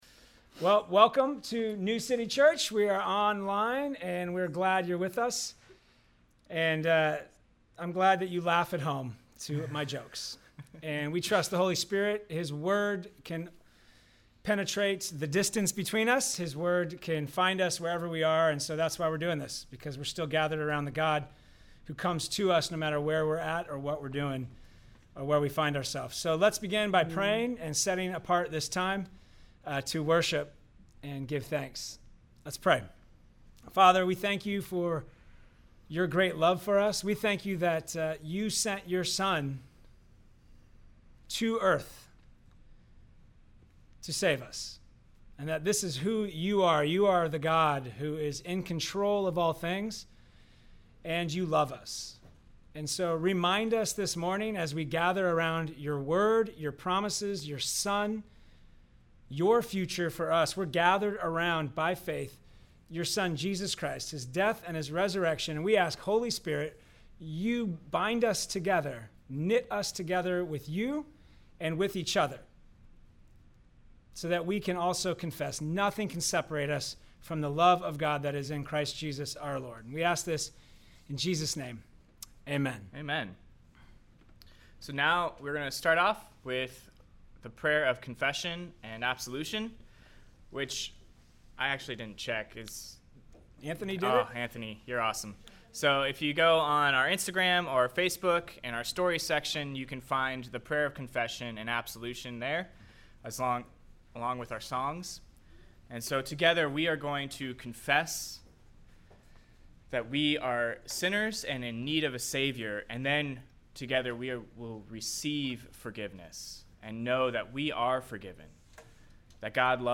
NCCO Sermons